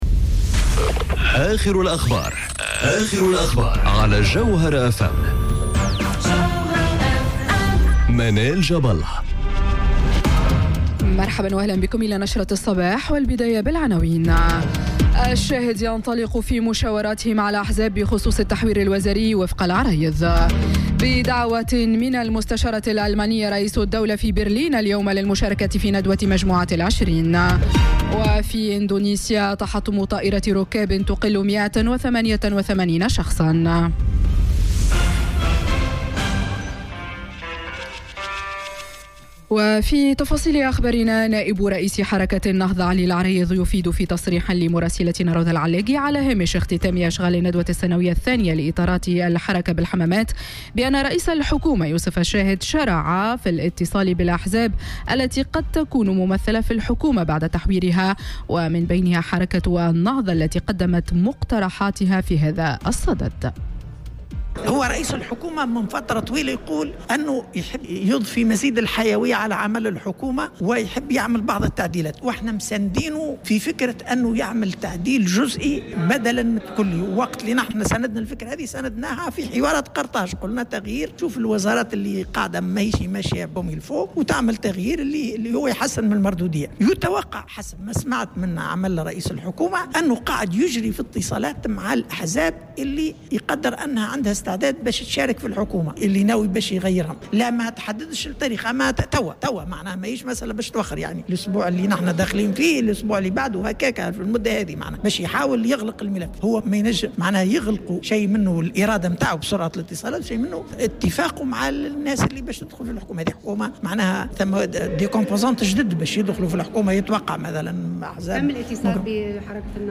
Journal Info 07h00 du lundi 29 octobre 2018